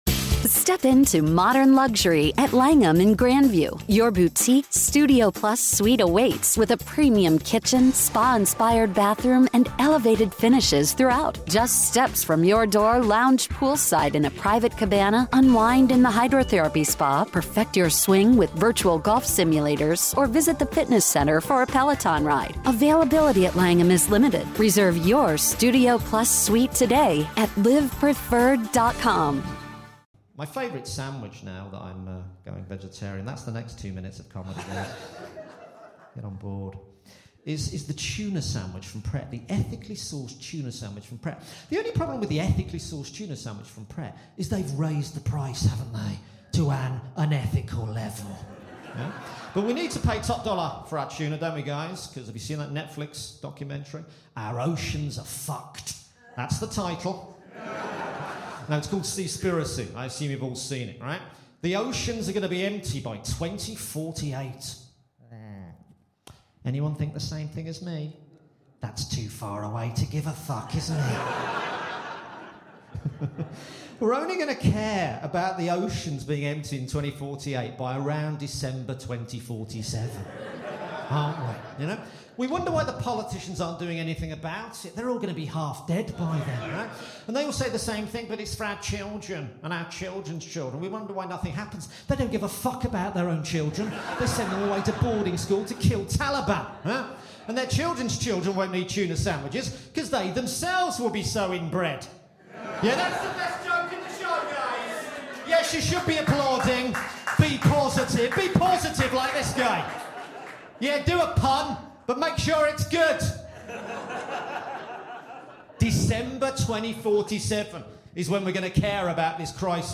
Recorded Live at Just The Tonic Edinburgh 2023.